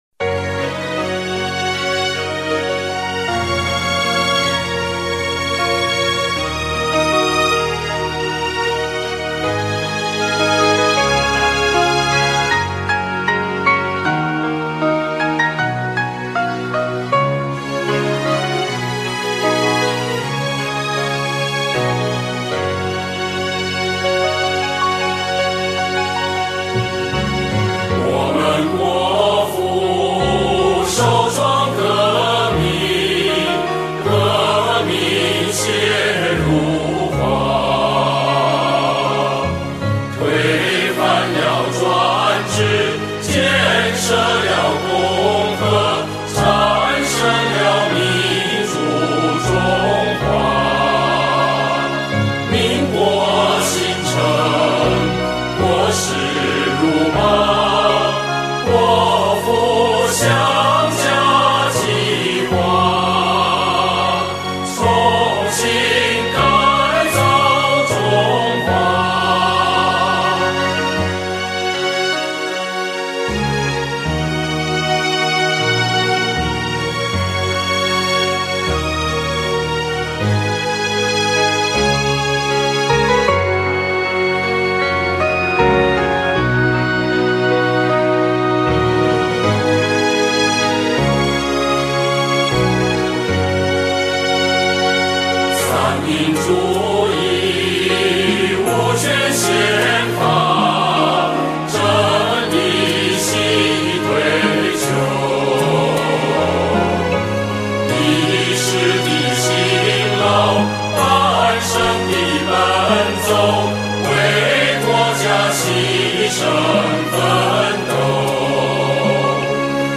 國歌